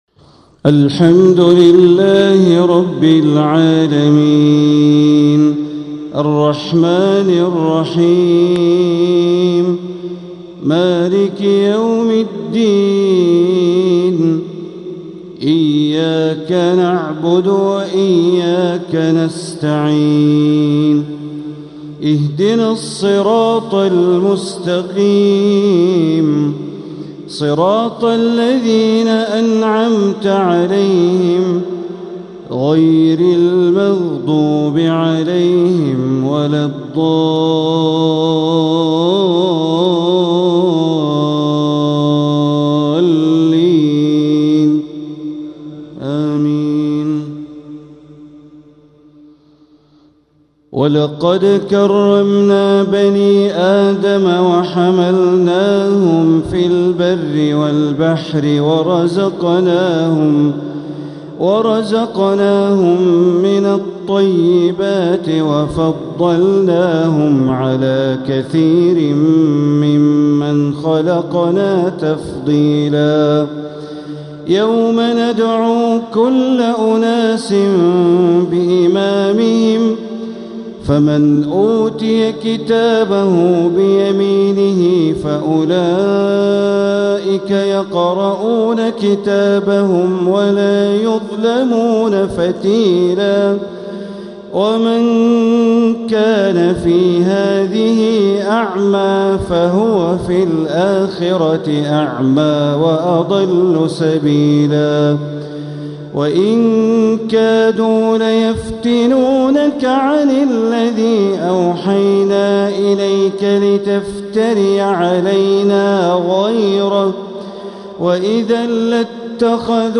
تلاوة من سورة الإسراء ٧٠-٨٤ | عشاء الإثنين ٣٠ ربيع الأول ١٤٤٧ > 1447هـ > الفروض - تلاوات بندر بليلة